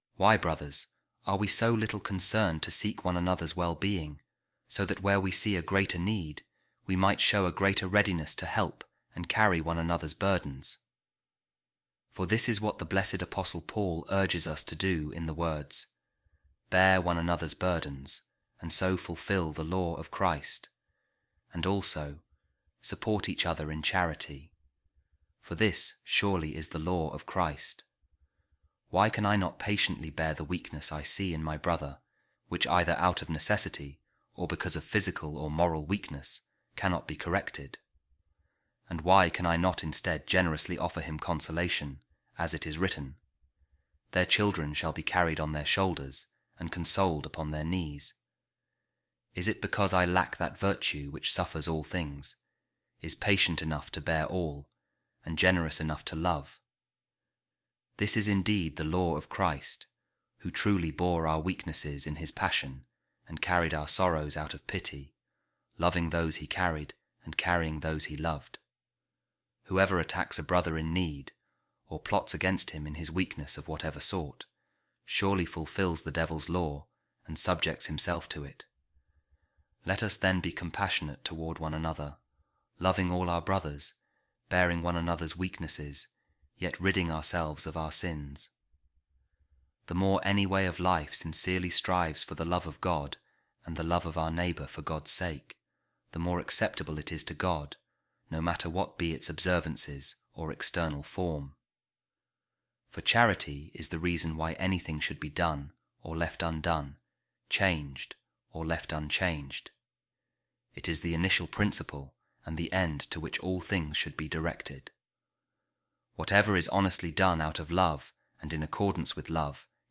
A Reading From A Sermon Of Blessed Saint Isaac Of Stella | The Supremacy Of Love
office-reading-saturday-5-isaac-stella.mp3